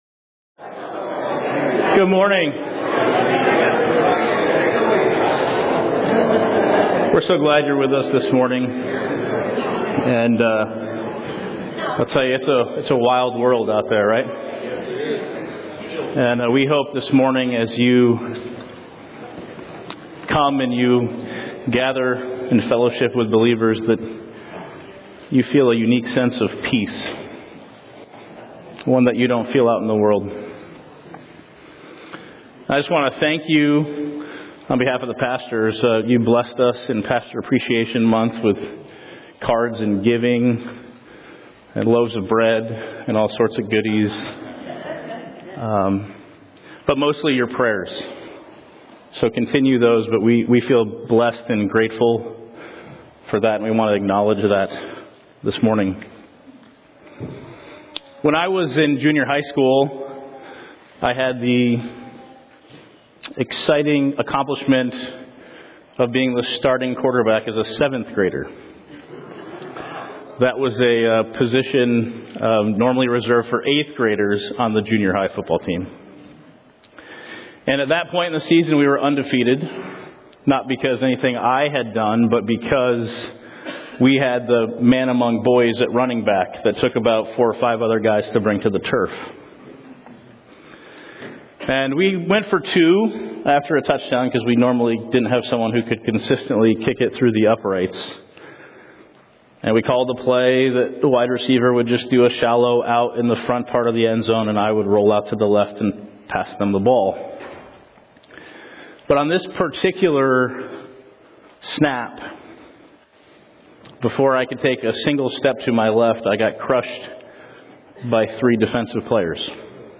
Series: 2024 Sermons